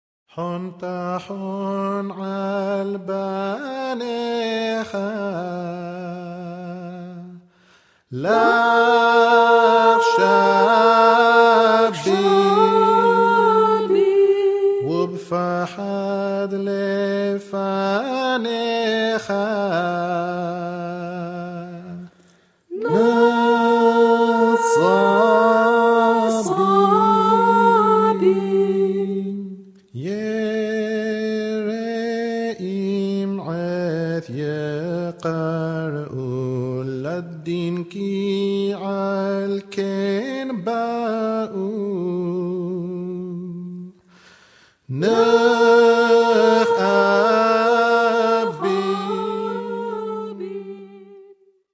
Iraqi Jewish and Arabic Song.